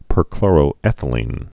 (pər-klôrō-ĕthə-lēn)